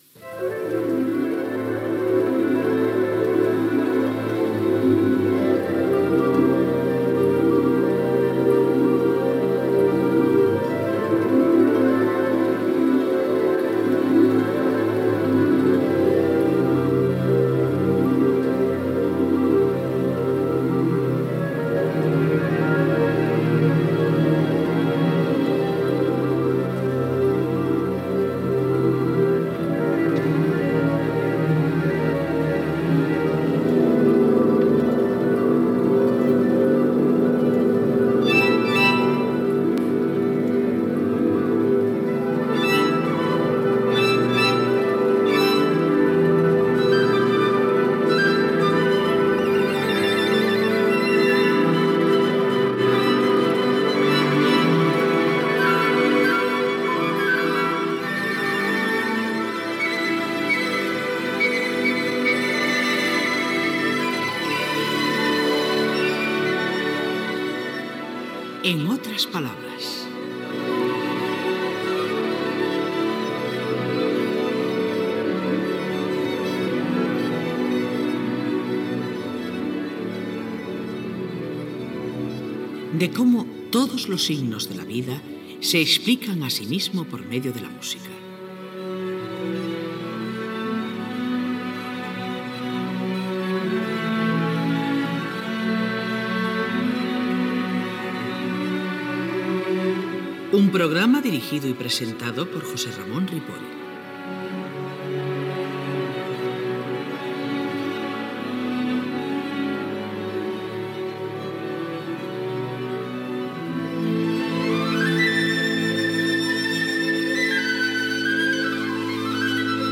Careta del programa, presentació, tema musical, entrevista al cantant Javier Krahe.
Musical
FM